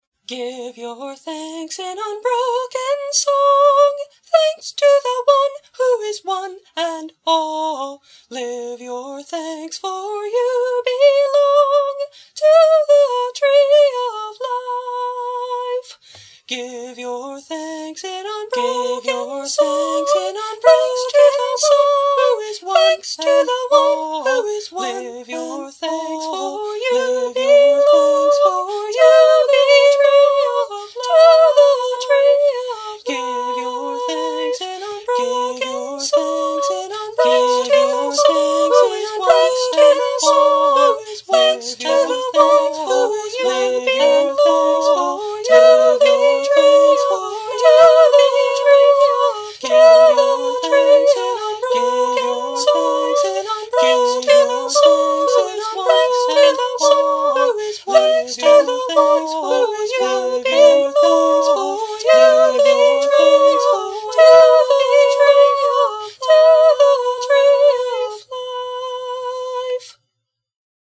A 3-part, thanksgiving round – please enjoy, remember, and share!